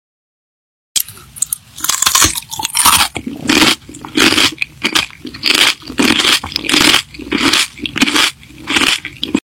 Watch this satisfying crunchy juicy sound effects free download
Watch this satisfying crunchy juicy ASMR video of a woman enjoying delicious sushi! 🍣 The crispy crunch and soothing sounds of each bite are sure to captivate and relax you. Perfect for *ASMR lovers*, *food enthusiasts*, and anyone who enjoys soothing eating sounds!